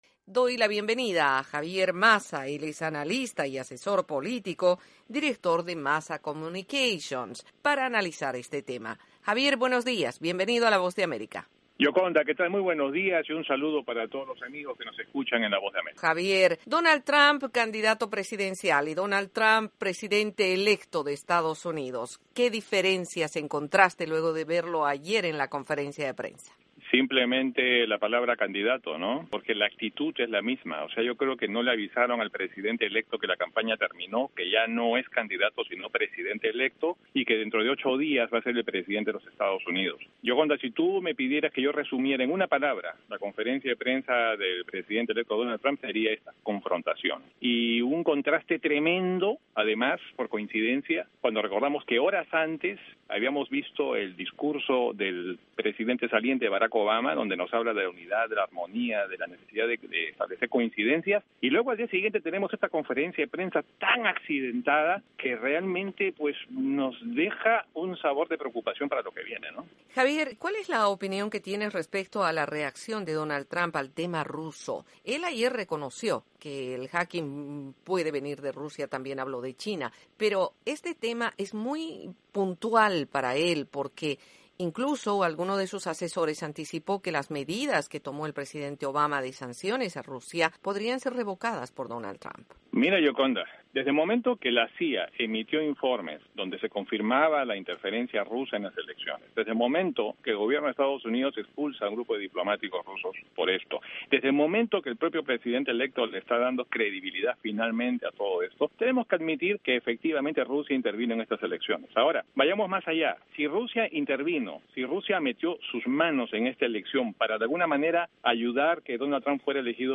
Entrevista con el asesor y analista político